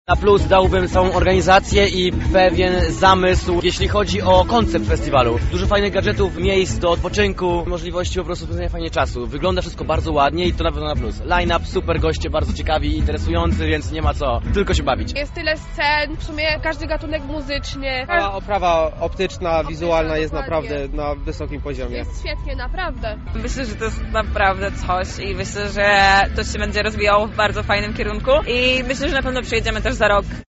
Zapytaliśmy publiczność, co sądzi o Fest Festivalu
relacja Fest Festival